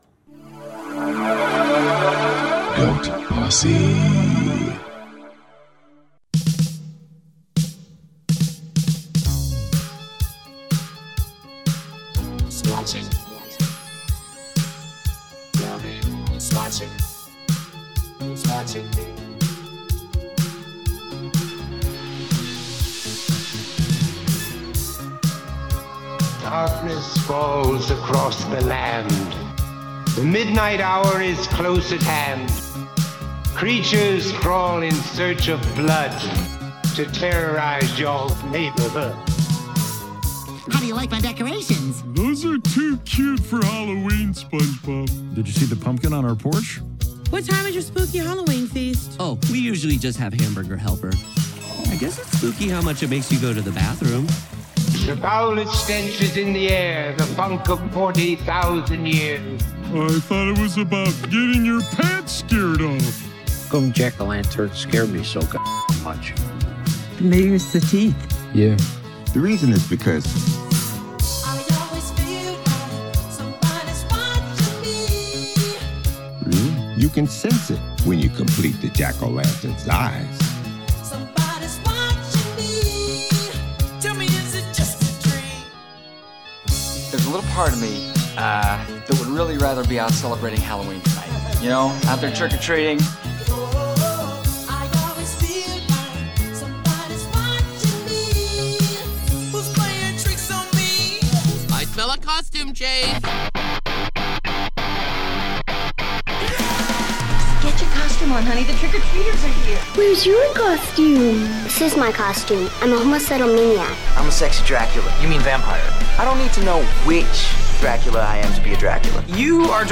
As it was heard on air…